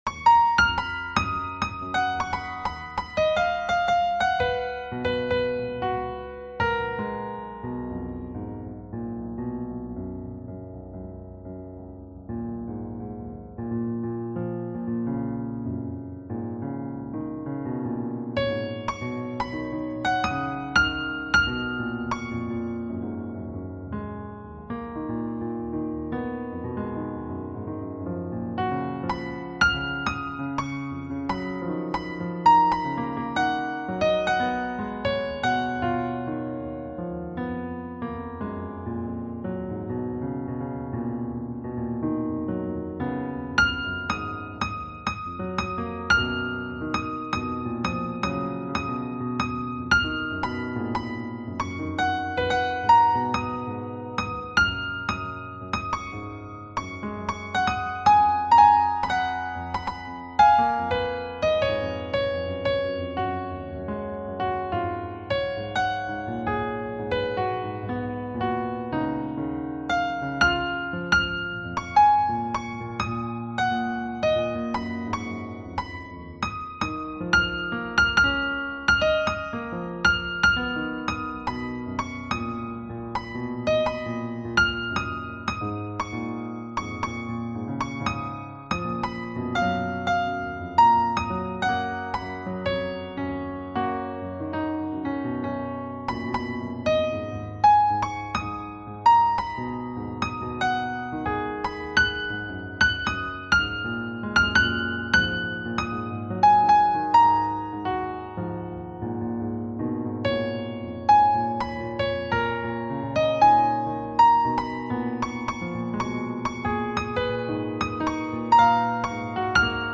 sonification